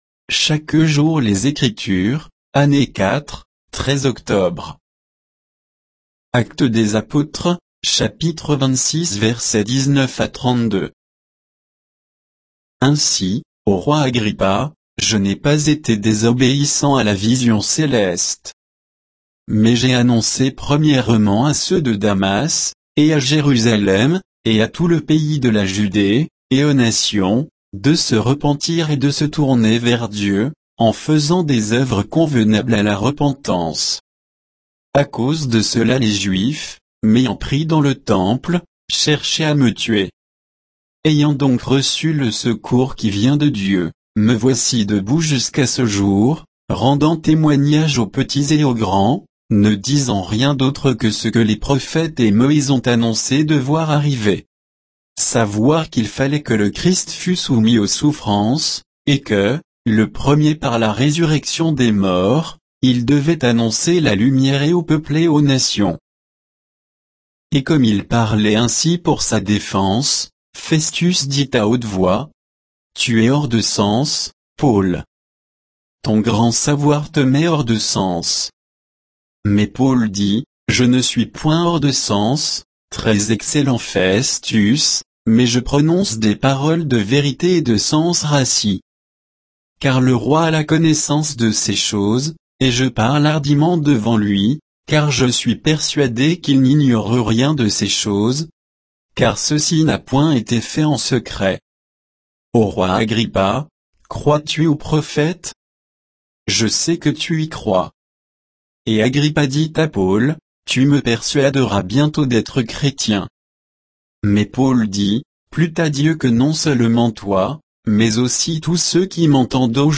Méditation quoditienne de Chaque jour les Écritures sur Actes 26, 19 à 32